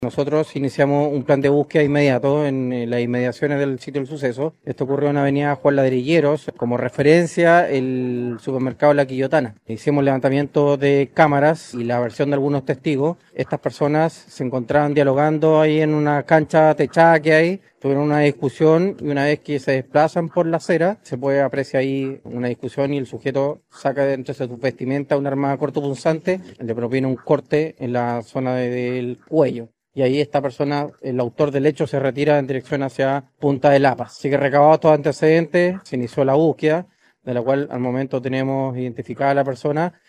En el momento se iniciaron las primeras indagatorias para dar con el atacante señaló el oficial de la policía uniformada, así como recabar los reportes de parte de testigos y evidencias con cámaras de seguridad.